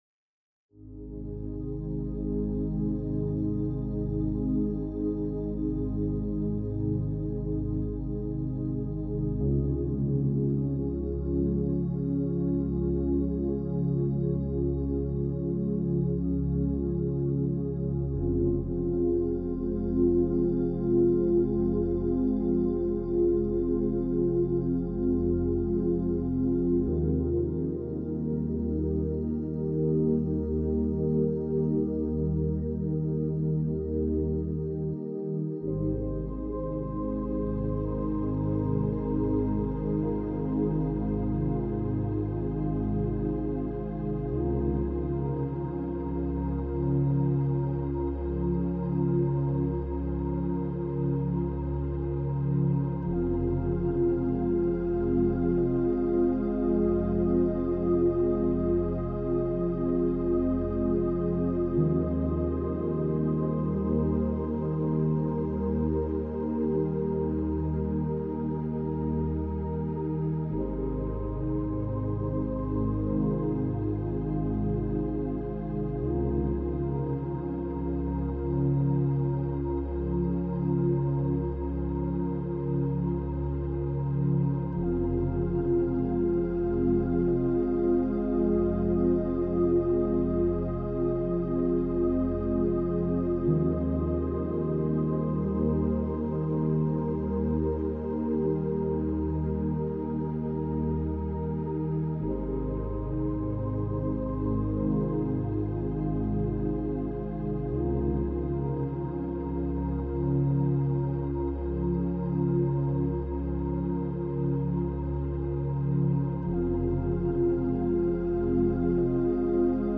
New Moon Energy - 24hz - Beta Binaural Beats for Quiet Focus + Intentional Beginnings ~ Binaural Beats Meditation for Sleep Podcast